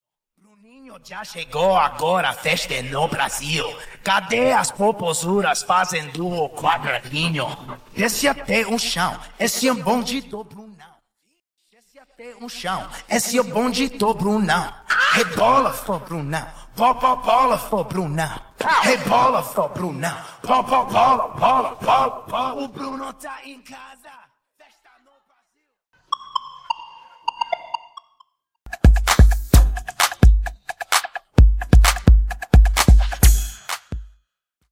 Backings Stem
Bassline Stem
Percussion & Drums Stem
Synths Stem